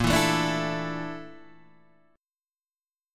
A# Major 7th Flat 5th